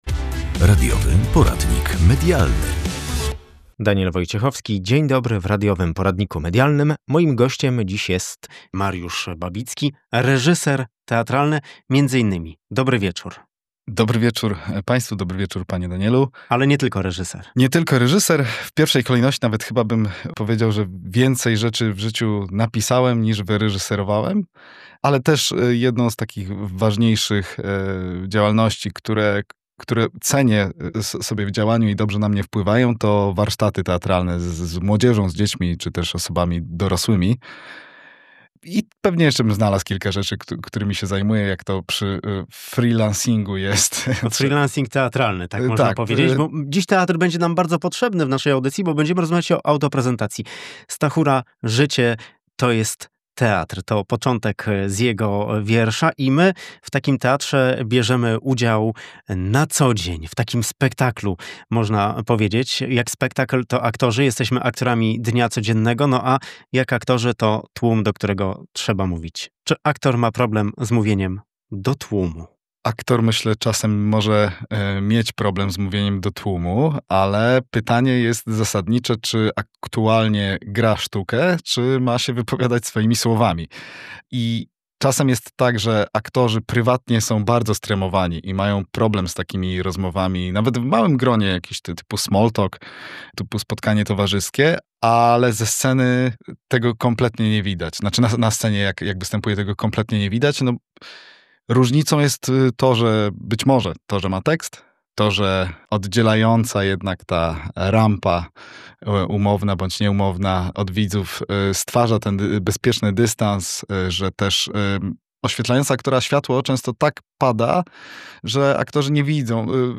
Aktorstwo w życiu codziennym. Posłuchaj rozmowy